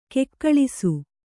♪ kekkaḷisu